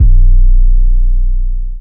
RM - Bink 808.wav